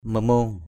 /mə-mo:ŋ/ (t.) dẻo (cơm) = cuit à point. cooked to perfection. lasei mamong ls] m_mU cơm dẻo = riz cuit à point. rice cooked to perfection.